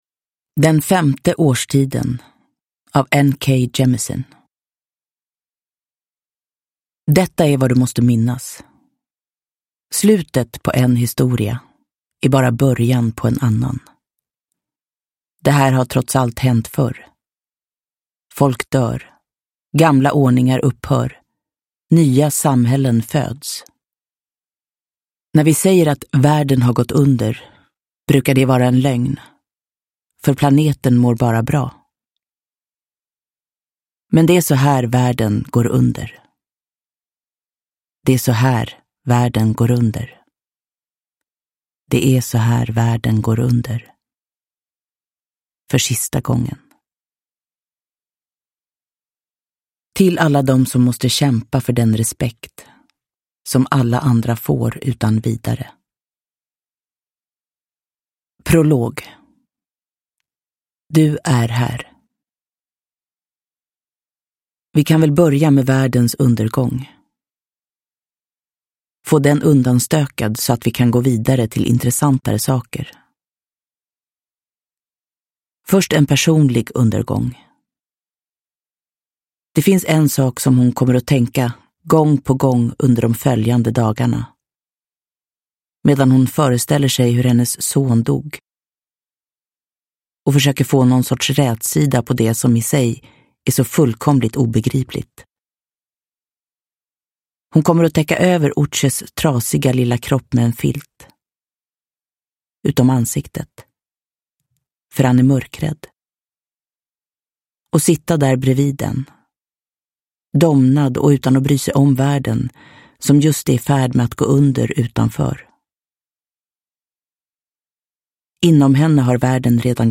Den femte årstiden – Ljudbok – Laddas ner
Uppläsare: Jessica Liedberg